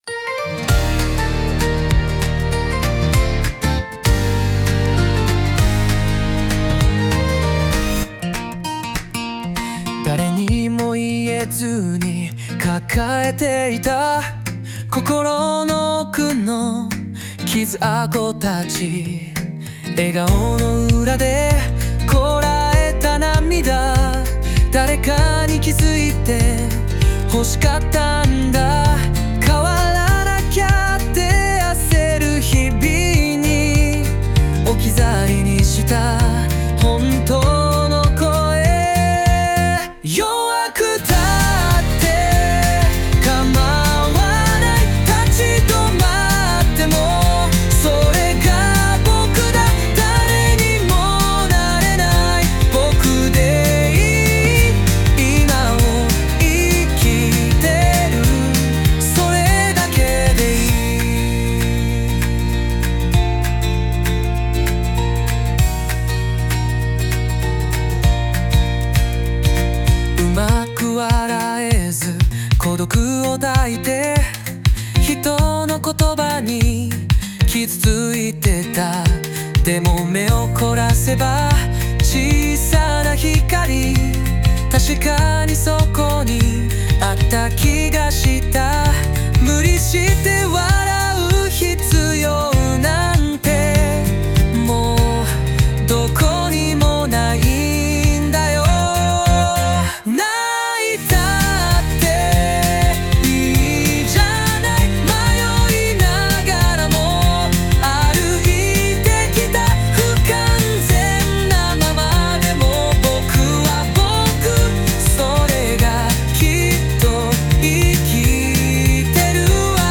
男性ボーカル邦楽 男性ボーカルプロフィールムービーエンドロール入場・再入場お色直し退場ポップスアップテンポ元気青春優しい
著作権フリーオリジナルBGMです。
男性ボーカル（邦楽・日本語）曲です。